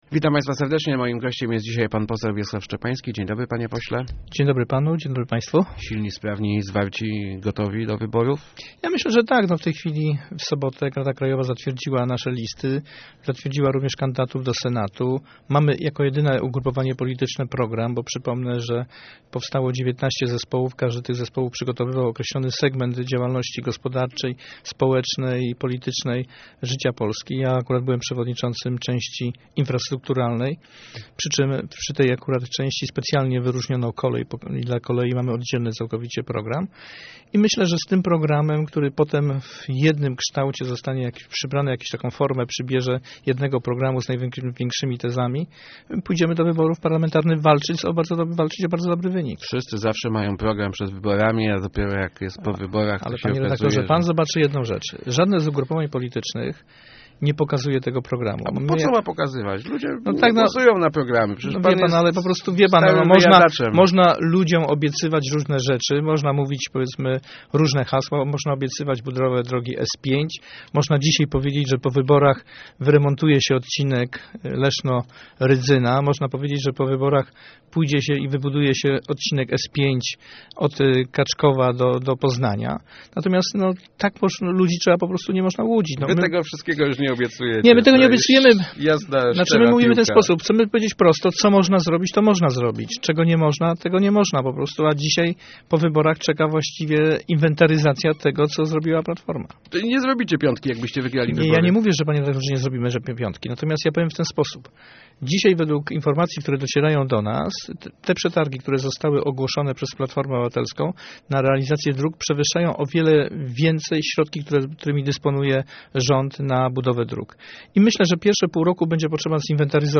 Liczymy na trzy albo cztery mandaty - mówił w Rozmowach Elki szef SLD w Lesznie, poseł Wiesław Szczepański.